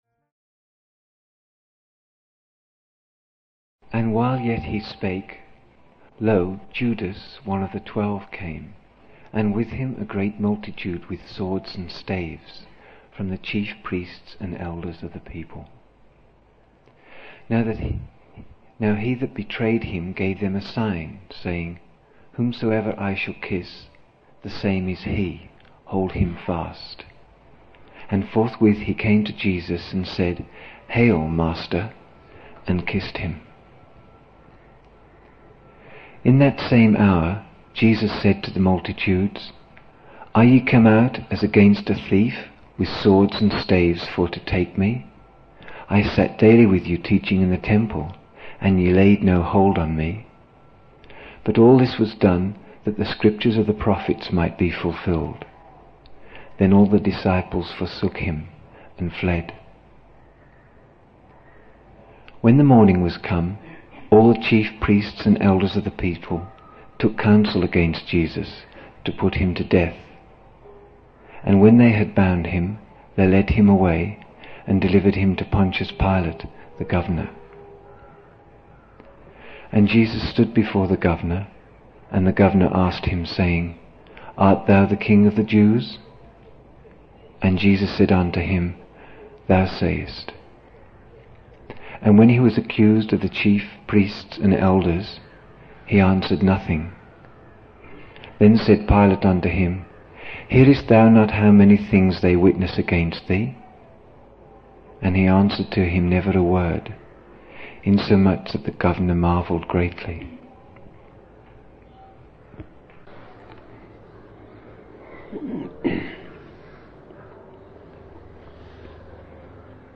27 October 1975 morning in Buddha Hall, Poona, India